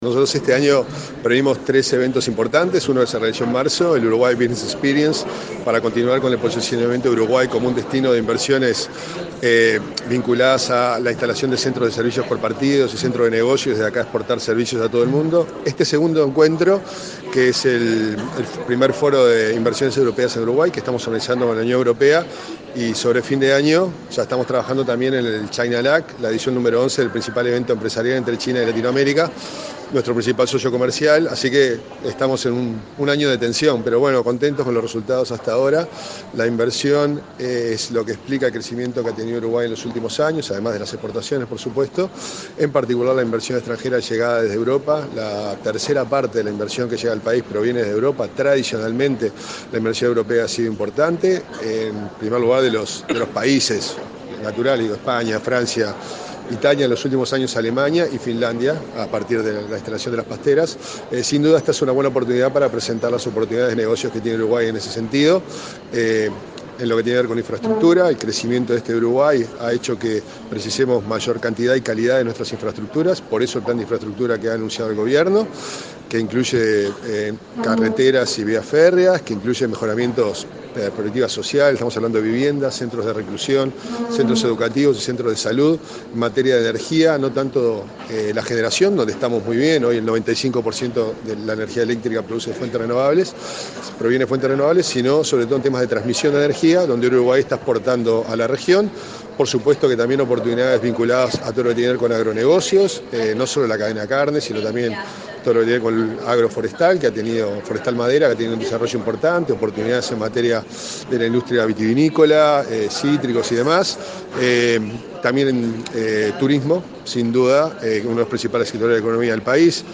La radicación de capitales externos y las exportaciones explican el crecimiento de la economía en los últimos 12 años, dijo el director de Uruguay XXI, Antonio Carámbula, en el foro Uruguay-Unión Europea. Destacó la importancia de la inversión europea en Uruguay, que implica la tercera parte de toda la que se recibe, especialmente de España, Francia, Italia, Alemania y, en los últimos años, de Finlandia.